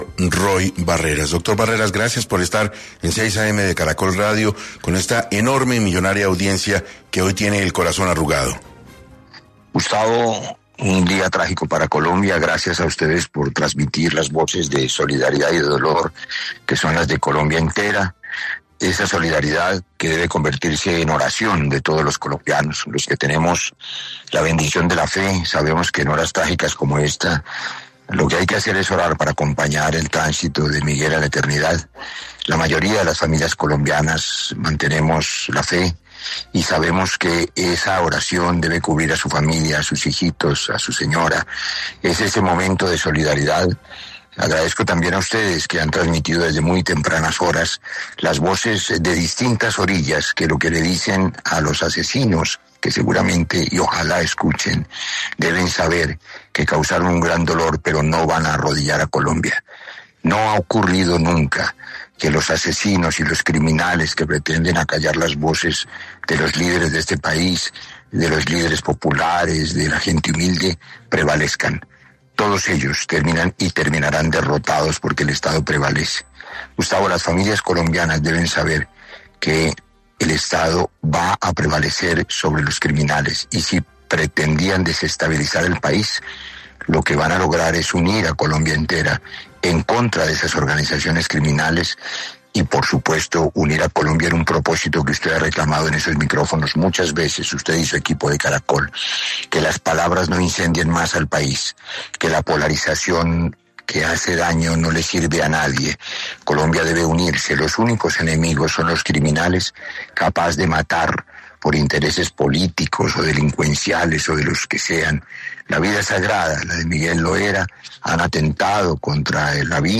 En un tono enérgico, Barreras señaló que, los criminales son una minoría, son cobardes porque se esconden en la penumbra, porque usan adolescentes como sicarios, pero son cobardes, son minoría, serán derrotados”.